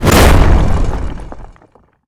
rock_blast_impact_projectile_01.wav